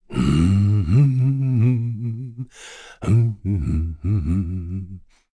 kingsraid-audio / voices / heroes / kr / Dakaris-Vox_Hum_kr.wav
Dakaris-Vox_Hum_kr.wav